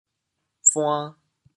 潮州 hia6 buan2 潮阳 hia6 buan2 潮州 0 1 潮阳 0 1